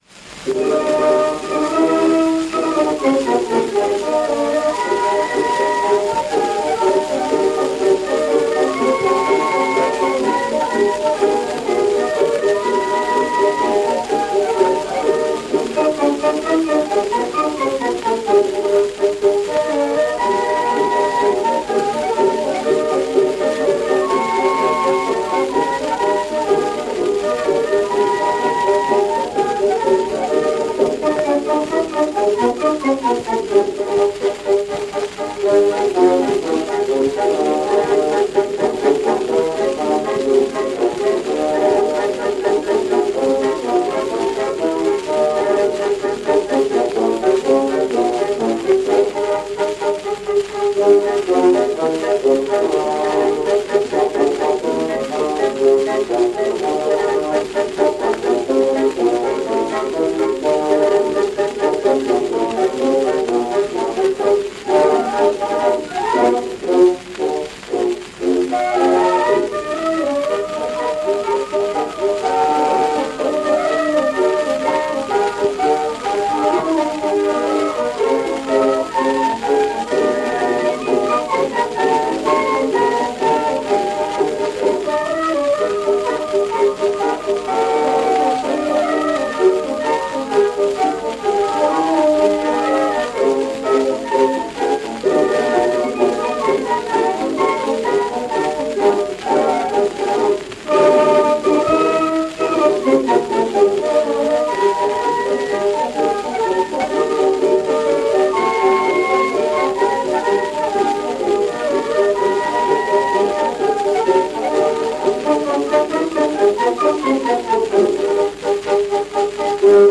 Давайте послушаем марш, который называется «Тоска по Родине» — один из самых красивых, самых знаменитых и самых загадочных наших маршей:
Марш «Тоска по Родине» (грамзапись Zonophone-X-60713 — июнь 1906 года)